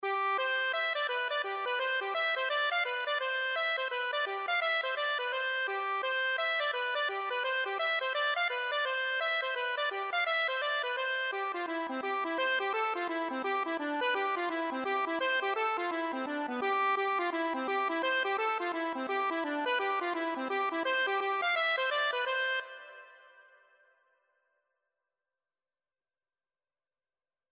Accordion version
C major (Sounding Pitch) (View more C major Music for Accordion )
4/4 (View more 4/4 Music)
Accordion  (View more Easy Accordion Music)
Traditional (View more Traditional Accordion Music)
world (View more world Accordion Music)